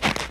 snow-03.ogg